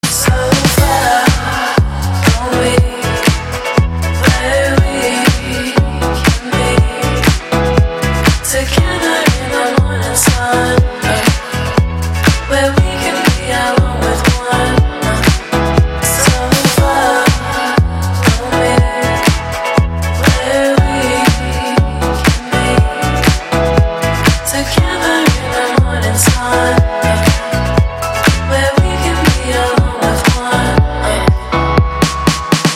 • Качество: 128, Stereo
чувственные
nu disco
теплые
Chill